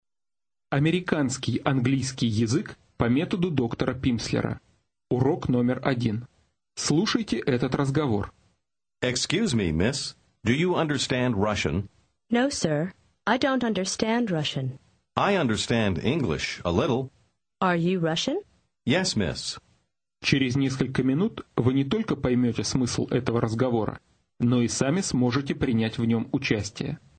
Аудио курс для самостоятельного изучения английского языка.